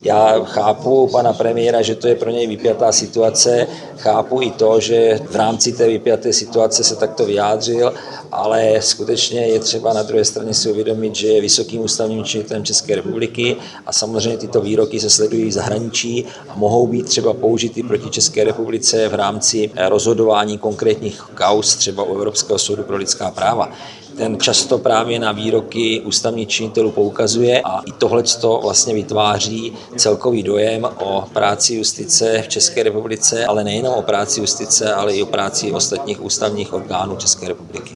Pavel Šámal hovoří ke slovům Babiše: